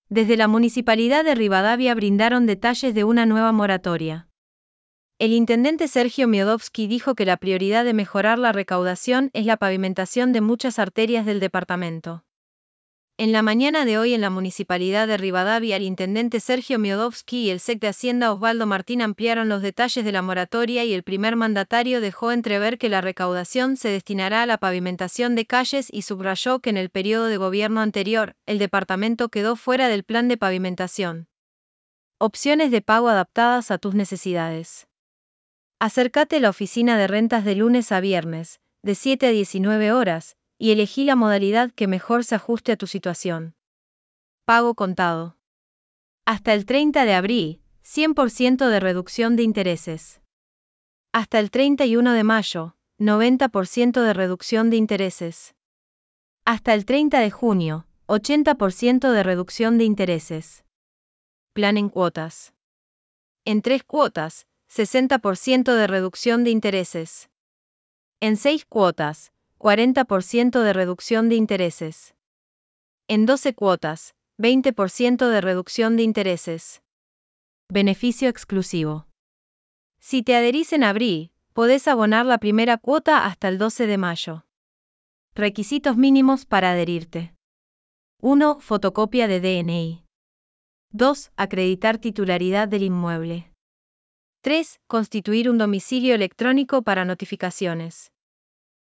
Text_to_Speech-2.wav